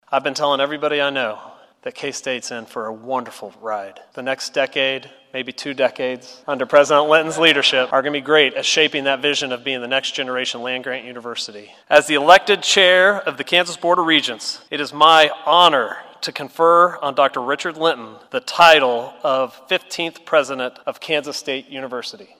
President Richard Linton officially took on the title following a ceremony held in McCain Auditorium.
Kansas Board of Regents Chair Jon Rolph had the honor of conferring the title of University President.